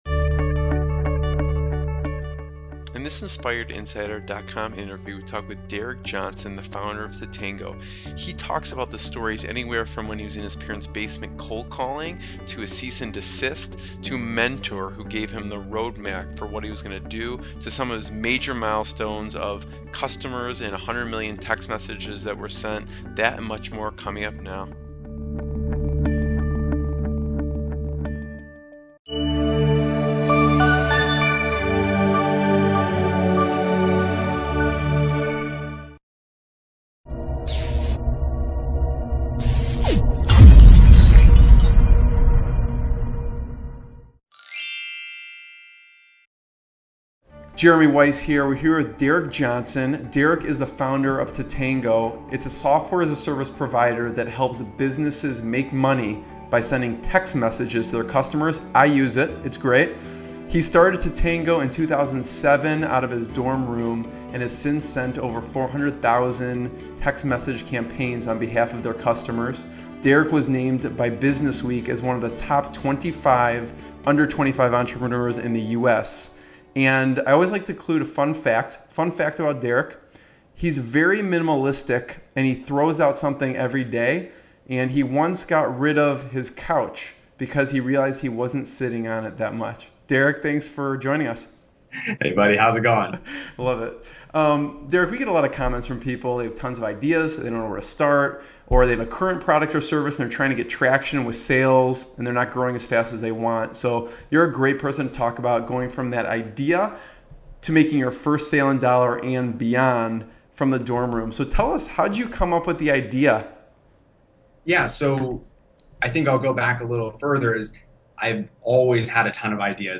What will you learn in this interview?